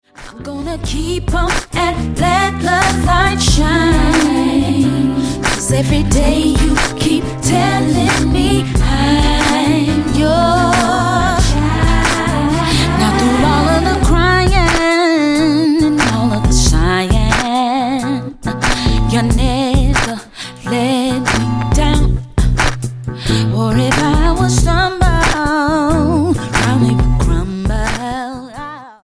STYLE: R&B